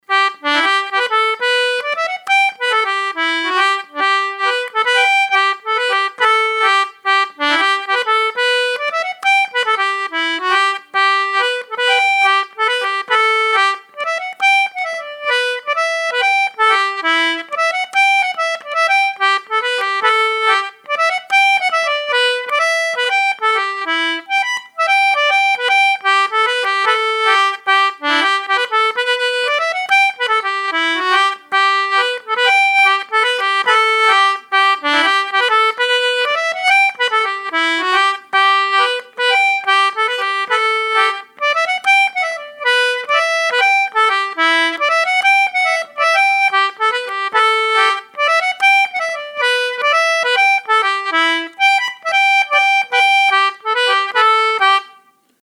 Recorded Edinburgh, 12 May 2022.